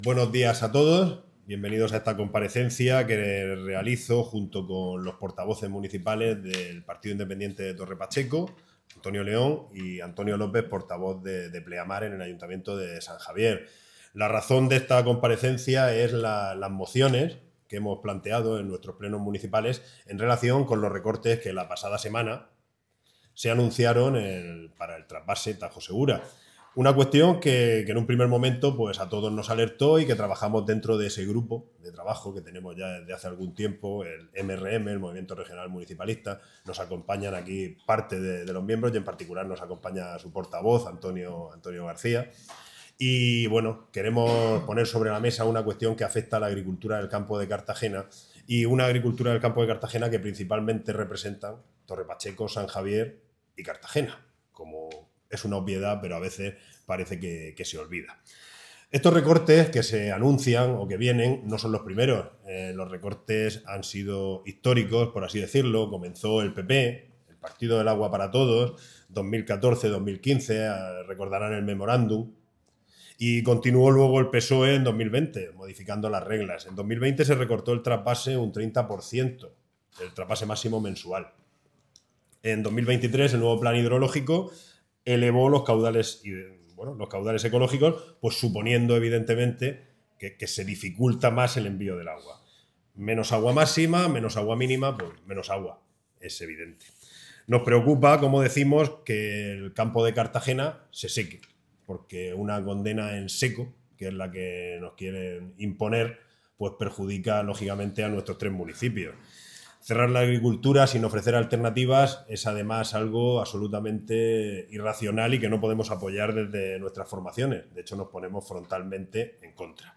Audio: Rueda de prensa de MC. (MP3 - 16,65 MB)